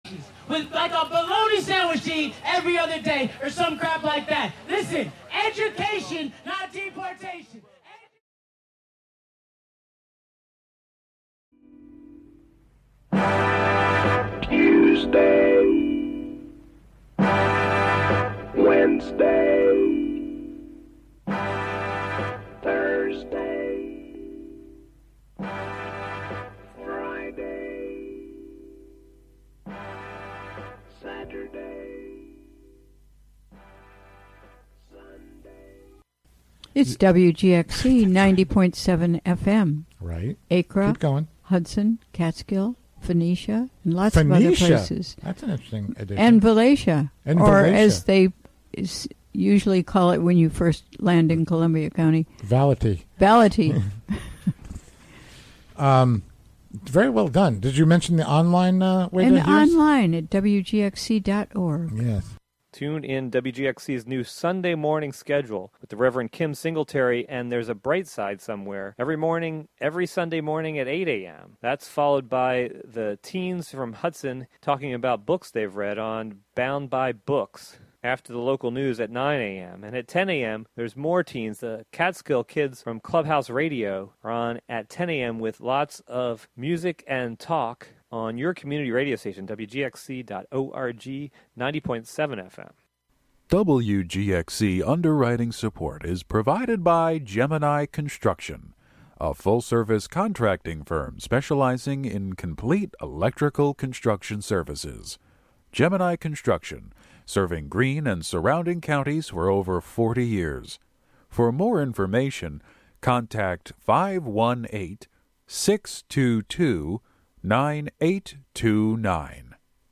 8pm In this broadcast, part two of an interview with T...